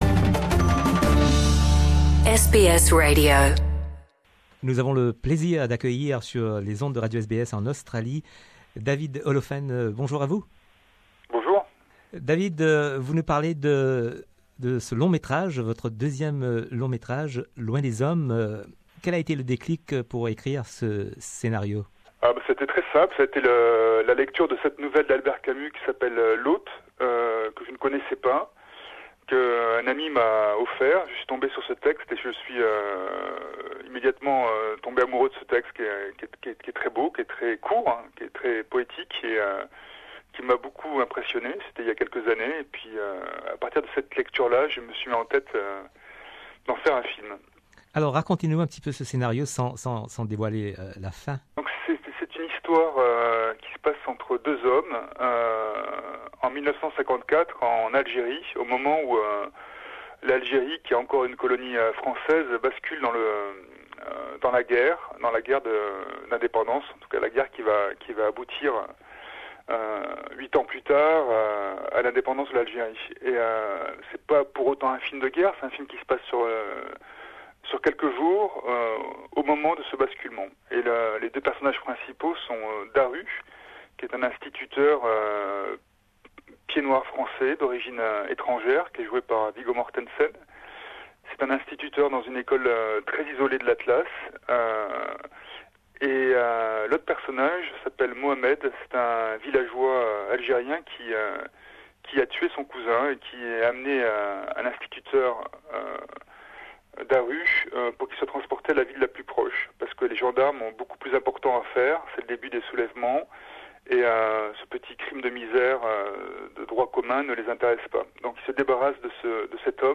Rediffusion de nos archives d'une conversation avec David Oelhoffen qui nous parle de son film Far from men ( d'après l'Hôte d'Albert Camus ).